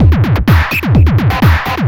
DS 127-BPM A3.wav